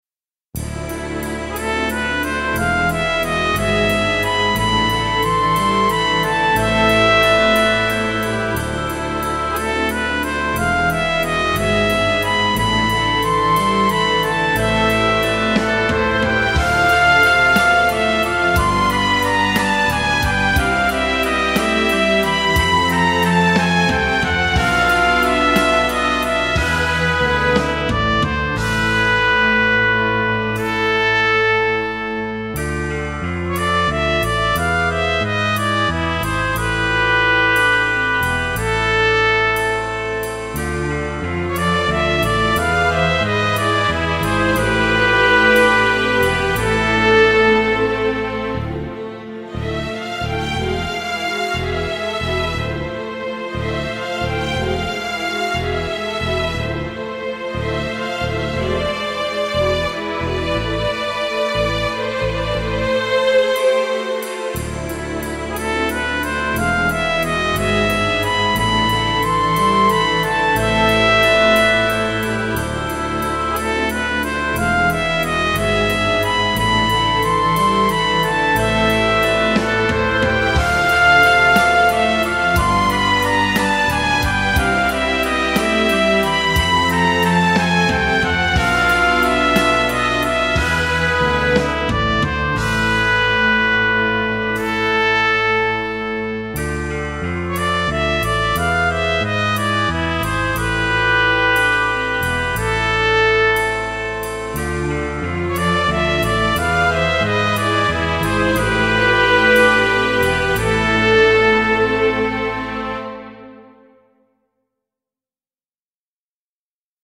2017.11 | 大人な雰囲気 | ジャズ | 1分57秒/1.78 MB